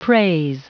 Prononciation du mot praise en anglais (fichier audio)
Prononciation du mot : praise